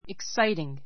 exciting 小 A1 iksáitiŋ イ ク サ イティン ぐ 形容詞 興奮させる, 手に汗 あせ を握 にぎ らせるような; わくわくする , とてもおもしろい an exciting game an exciting game （手に汗を握らせる）とてもおもしろい試合 How exciting!